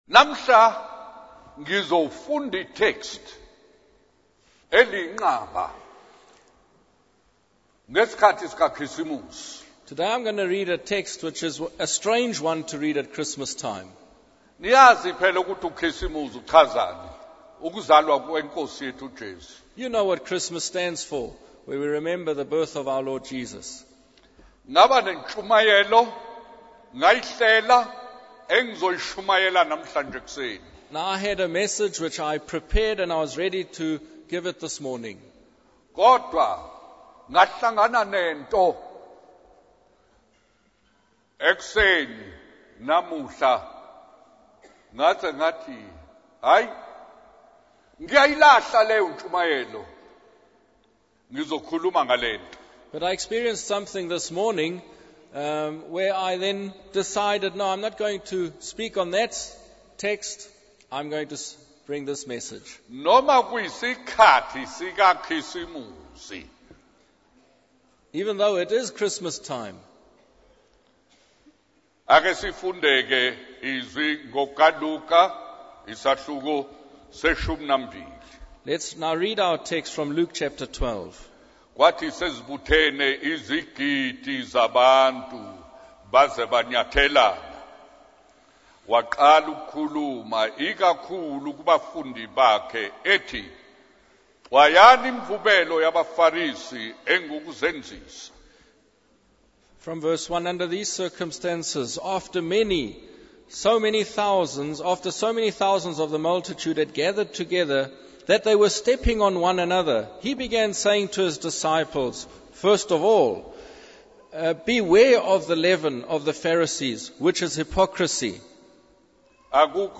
He highlights the idea that nothing hidden will remain concealed and that all things will be revealed. The preacher shares a story about three naughty boys who sent telegrams to high-ranking officials, causing them to fear the exposure of their misdeeds. The sermon references Bible verses from Luke 12, 1 Corinthians 4, and Hebrews 4 to emphasize the need to give account for our actions, thoughts, and motives before God.